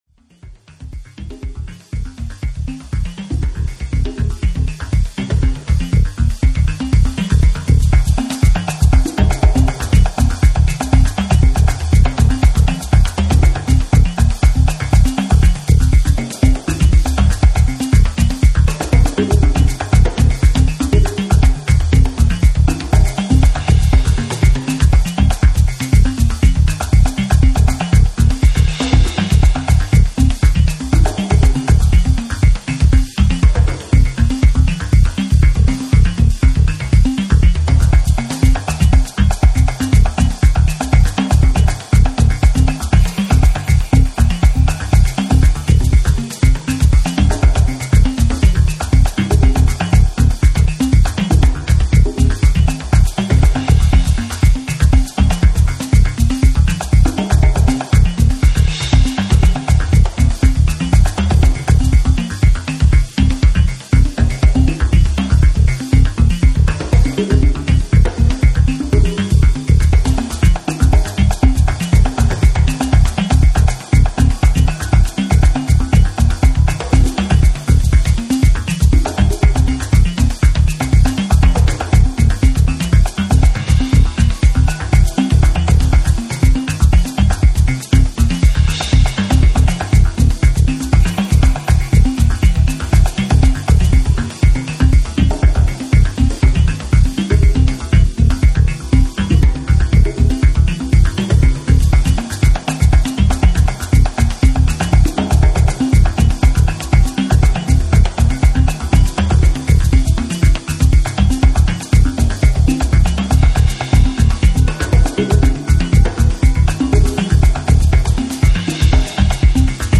JAPANESE / TECHNO & HOUSE / NEW RELEASE(新譜)